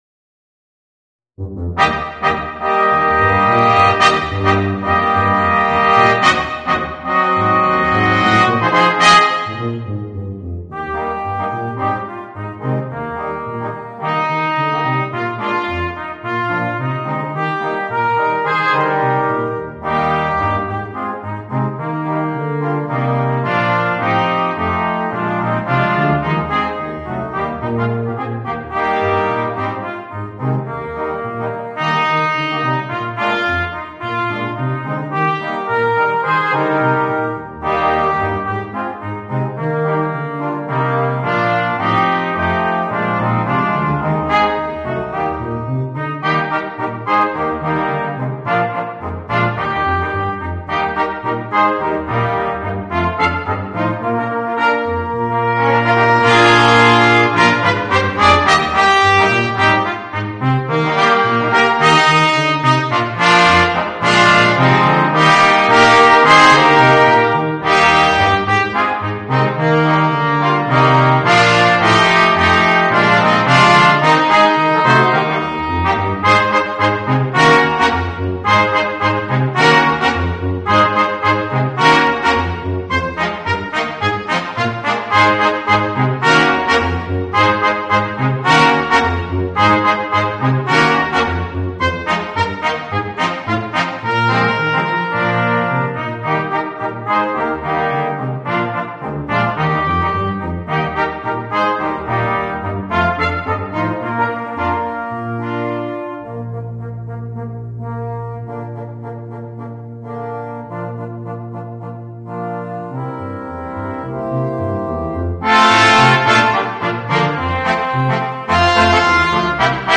Voicing: 2 Trumpets, Horn, Trombone and Tuba